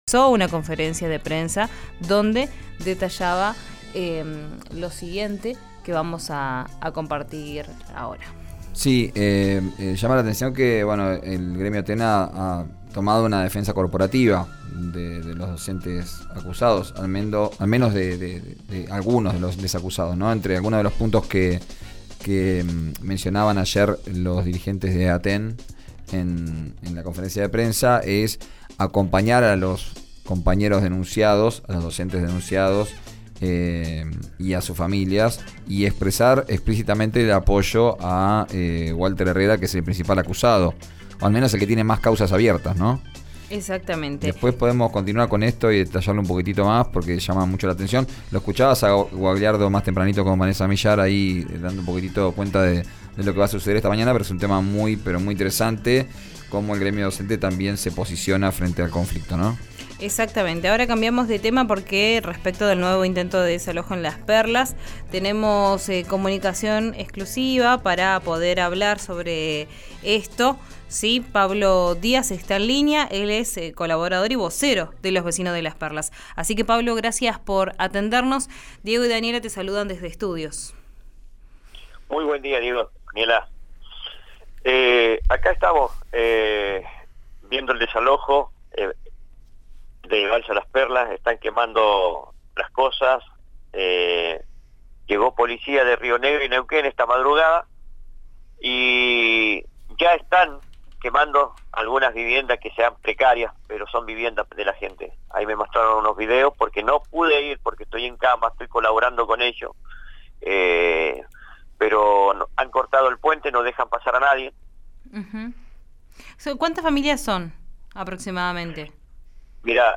indicó en RÍO NEGRO RADIO que están quemando las viviendas, cortando el puente y contó cómo está la situación en el lugar.